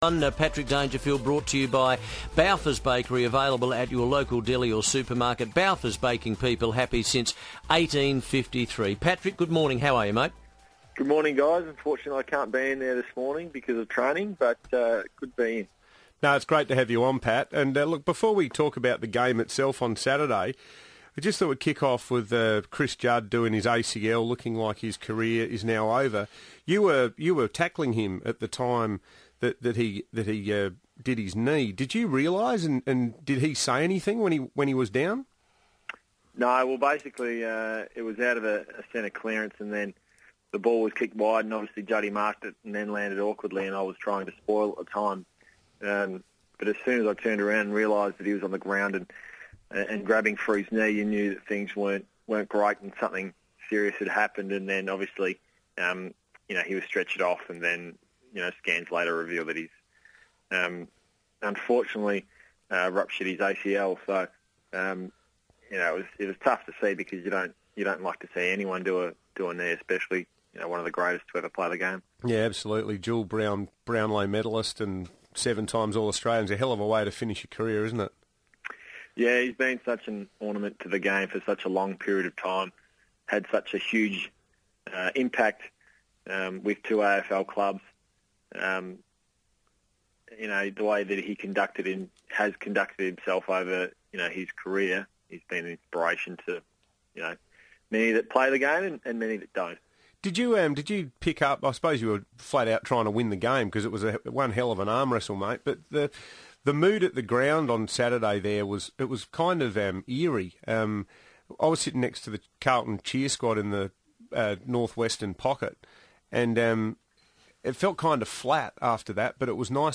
Crows midfielder Patrick Dangerfield joined the FIVEaa Breakfast panel after Adelaide's clutch win over Carlton